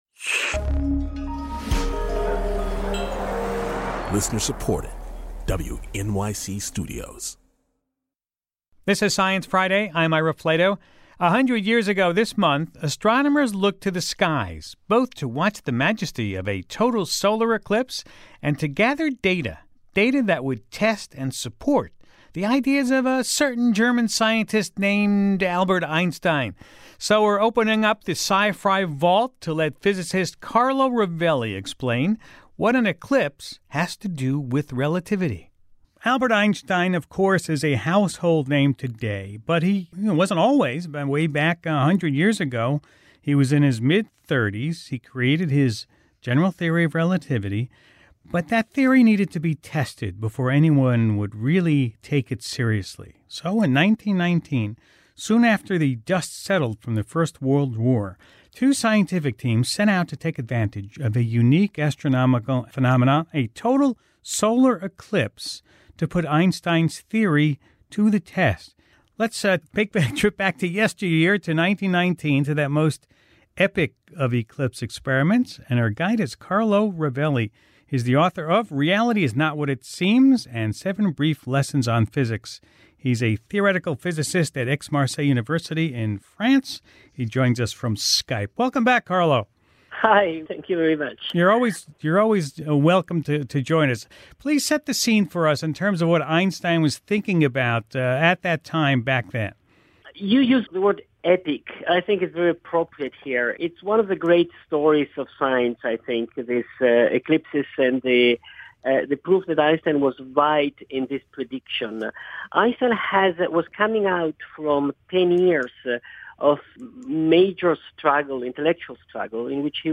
Carlo Rovelli, physicist and author, tells Ira the story.